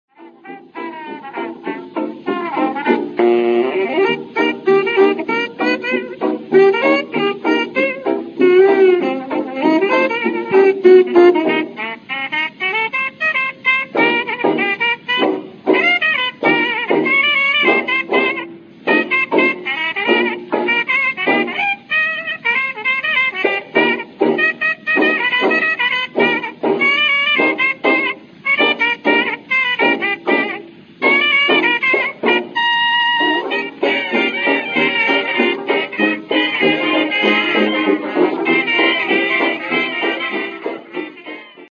Rispetto a quella versione il tempo è più brillante